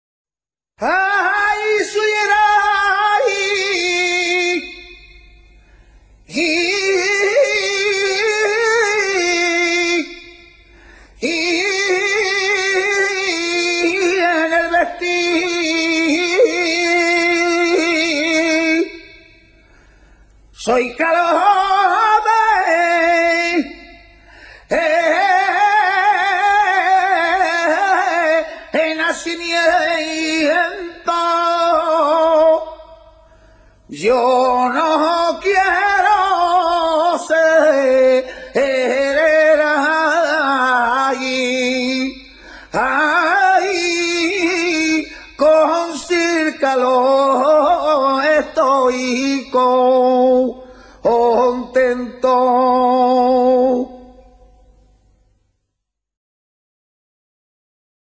Cante con copla de cuatro versos, que como la carcelera, el martinete, la nana, la saeta y las dem�s ton�s, pertenece al grupo de cantes sin guitarra, siendo su ornamentaci�n melism�tica mas profusa que los dem�s del grupo.
debla.mp3